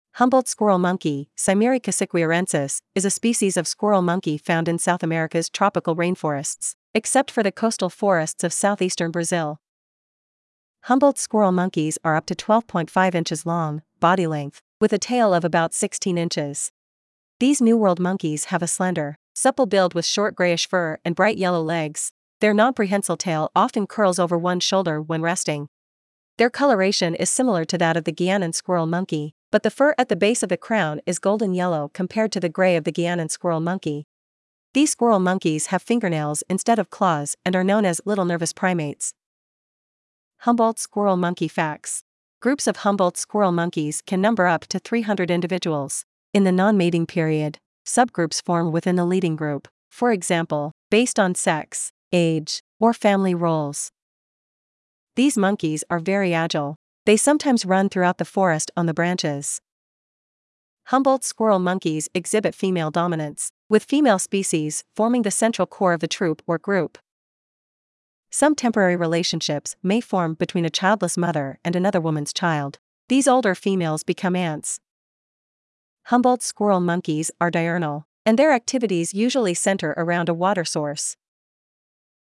Humboldt’s Squirrel Monkey
• Habitat: Forest, rainforest
Humboldts-squirrel-monkey.mp3